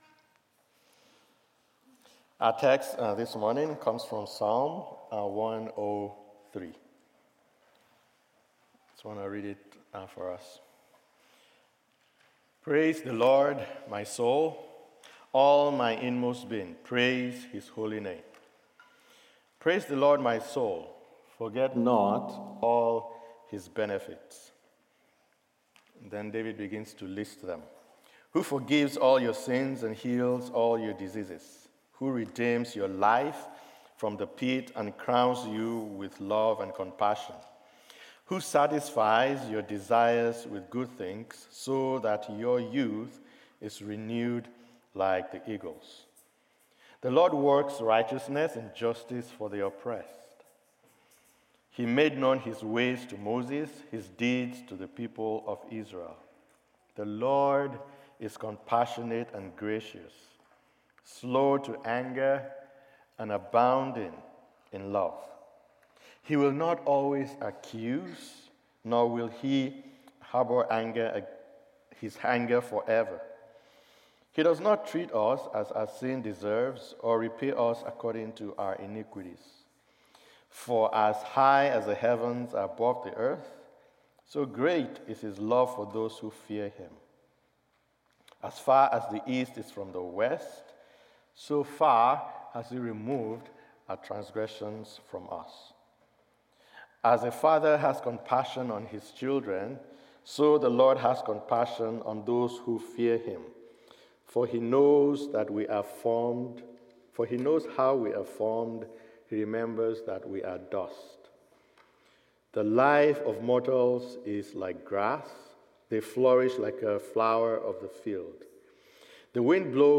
Sermons | Community Christian Reformed Church
Guest Preacher